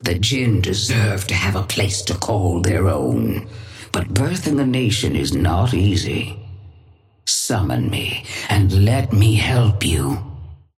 Sapphire Flame voice line - The Djinn deserve to have a place to call their own, but birthing a nation is not easy.
Patron_female_ally_mirage_start_04.mp3